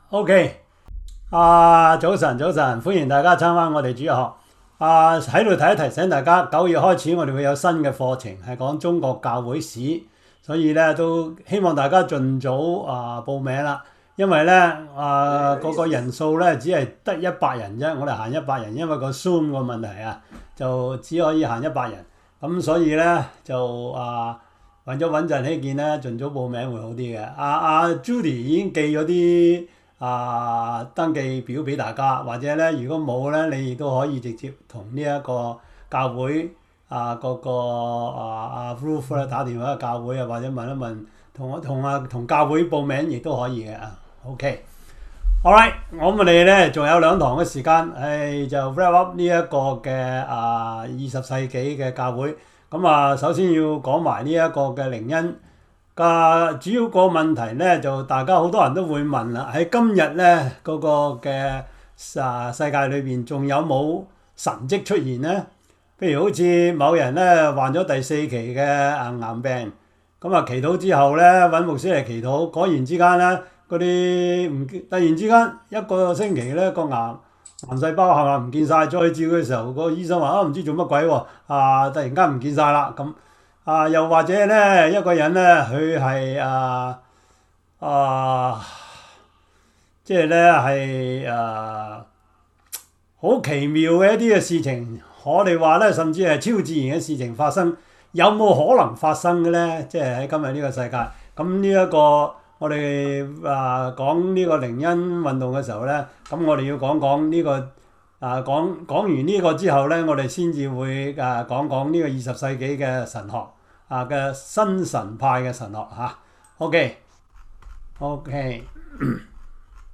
Series: 中文主日學, 教會歷史